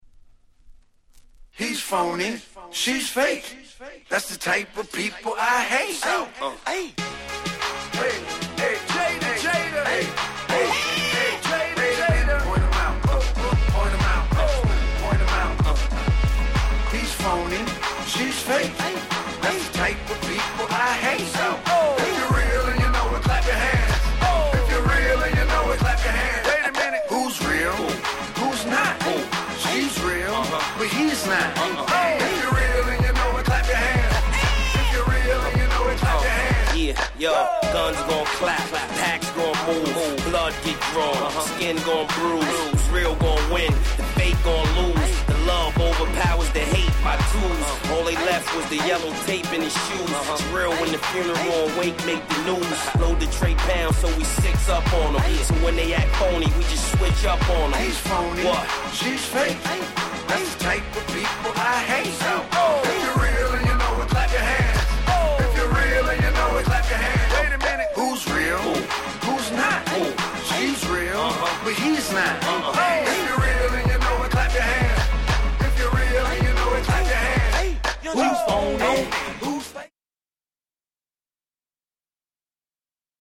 09' Big Hit Hip Hop !!
サビでみんなでハンドクラップする鉄板曲でもあります。